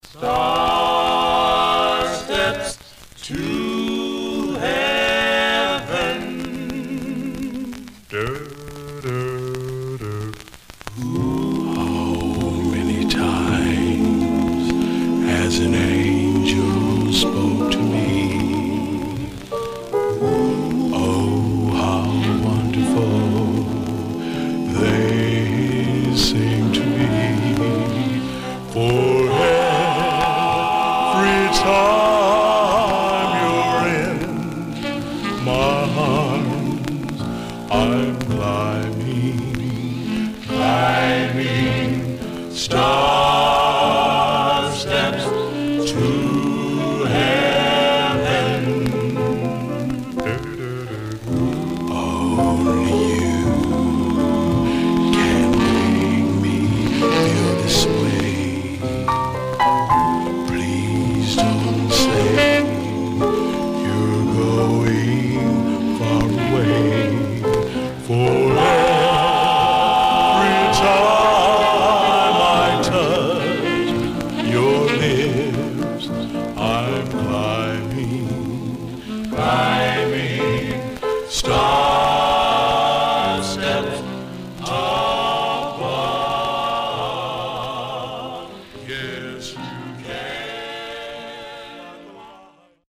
Surface noise/wear Stereo/mono Mono
Male Black Group